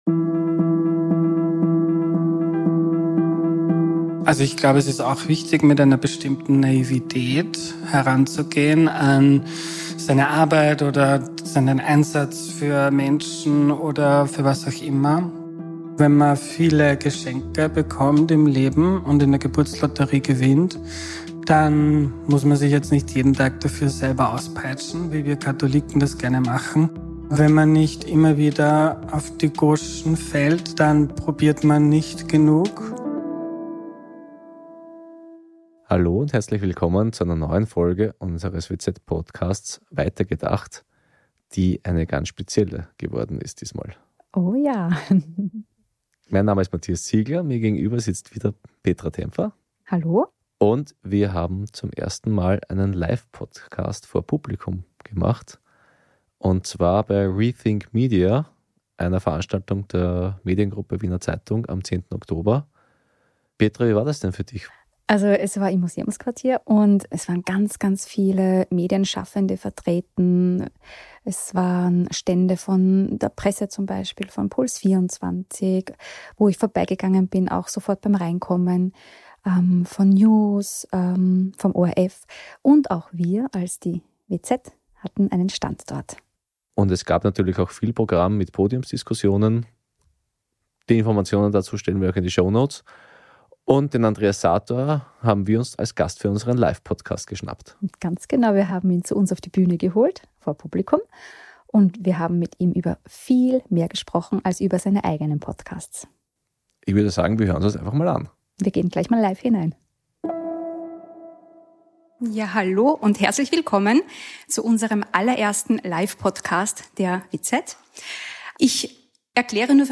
#47 Live-Podcast